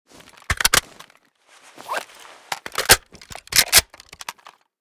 ak12_reload_empty.ogg.bak